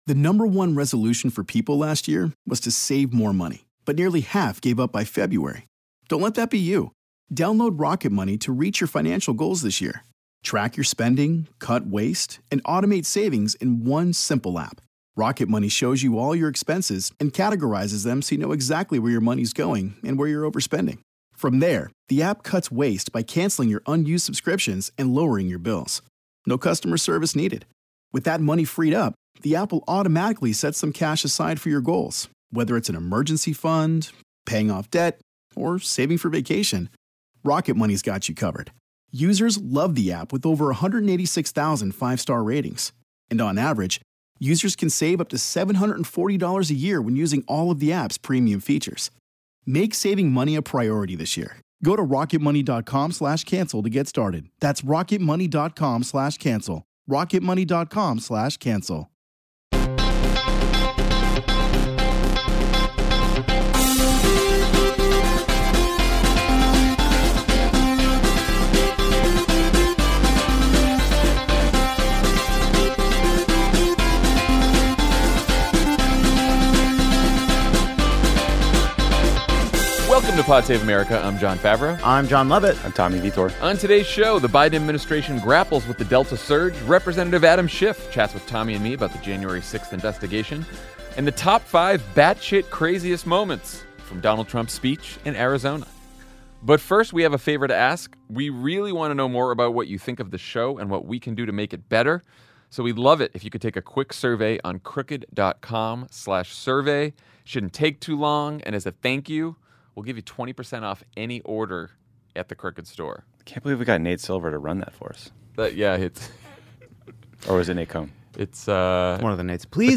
The Biden Administration grapples with the Delta surge, Representative Adam Schiff (D-CA) talks to Tommy Vietor and Jon Favreau about the January 6th investigation, and the Top Five Wildest Moments from Donald Trump’s speech in Arizona.